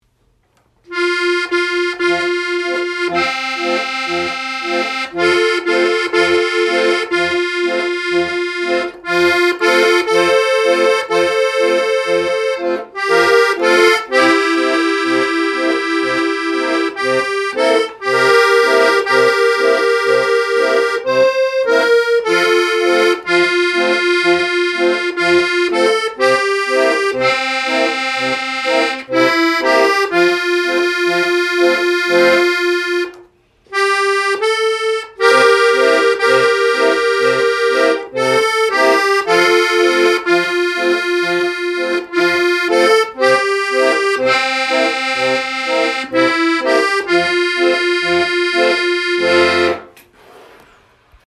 musikalischer Vortrag mit dem Akkordeon (Beurteilung anhand Hörproben)